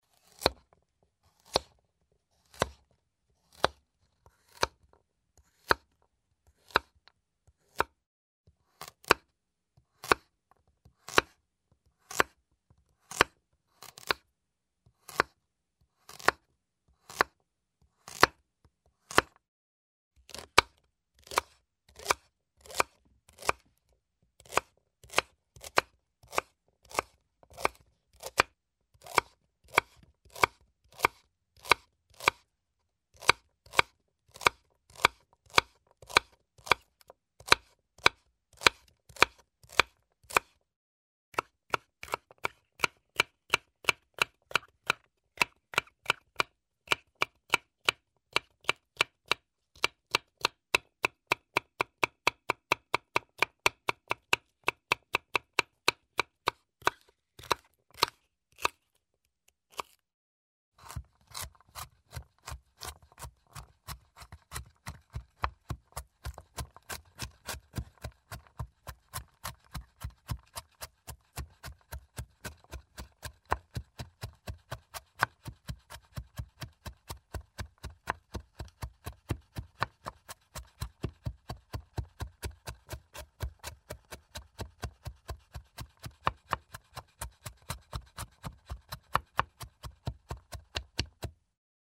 Звуки кухни, жарки
Нарезка овощей на доске для разделки